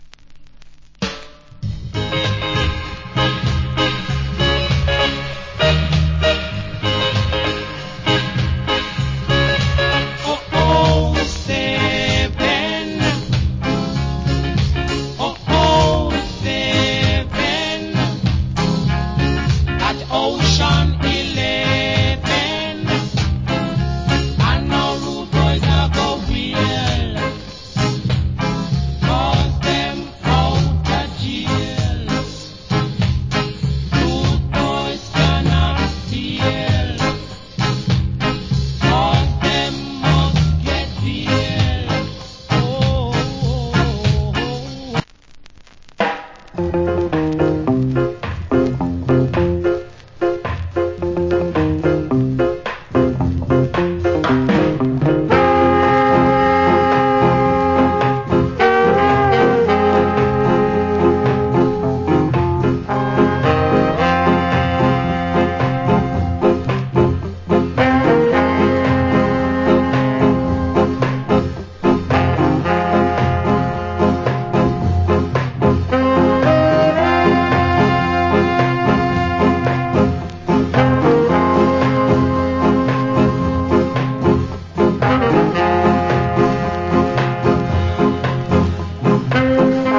Rock Steady Vocal.